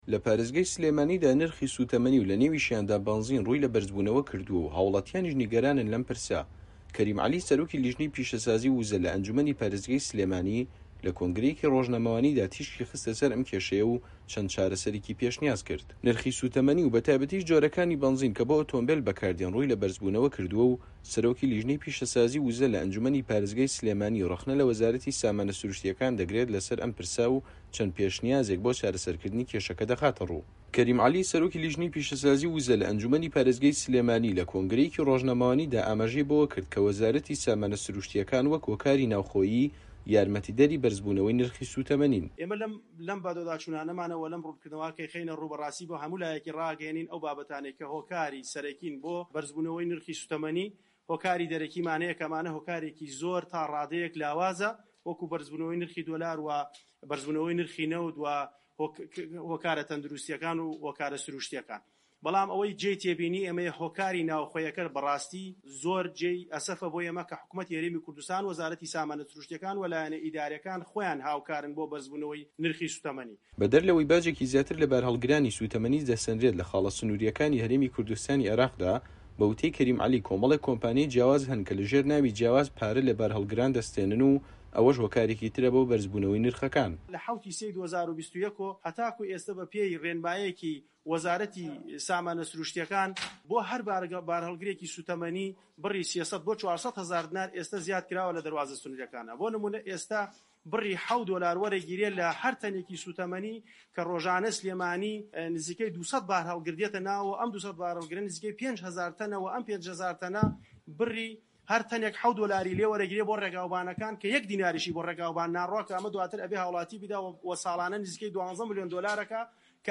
کەریم عەلی، سەرۆکی لیژنەی پیشەسازی و وزە لە ئەنجوومەنی پارێزگای سلێمانی لە کۆنگرەیەکی ڕۆژنامەوانیدا ئاماژەی بەوە کرد کە وەزارەتی سامانە سرووشتییەکان وەک هۆکاری ناوخۆیی یارمەتیدەری بەرزبوونەوەی نرخی سووتەمەنین.